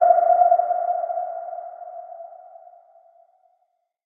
ping.ogg